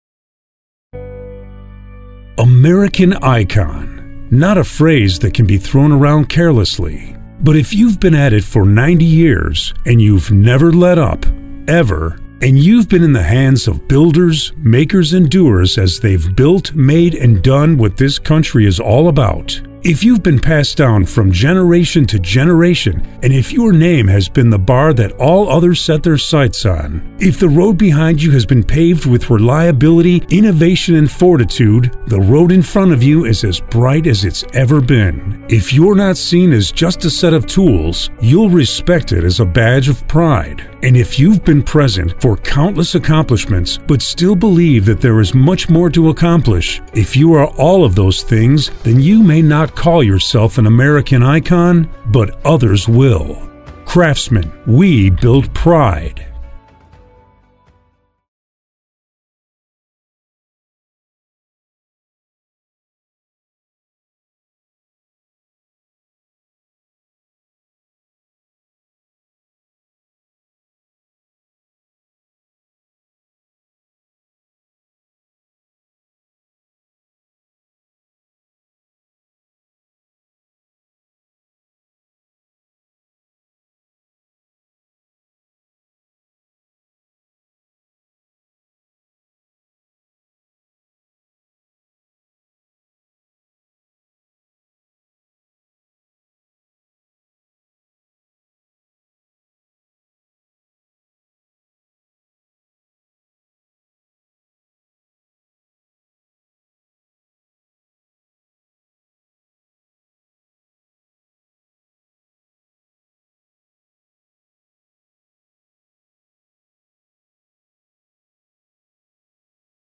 Midwest accent
Middle Aged